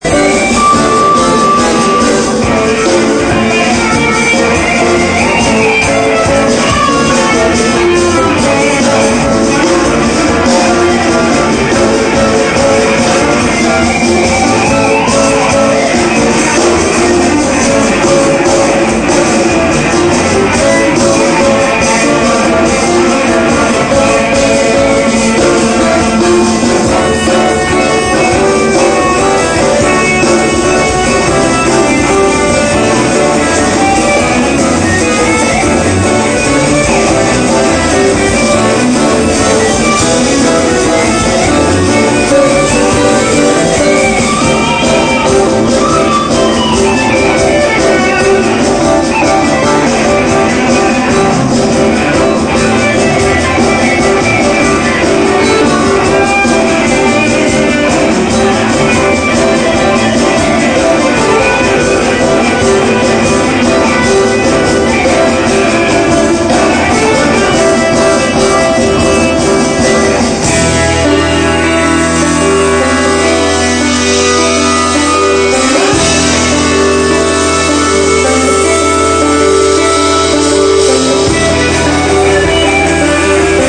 EN VIVO!!!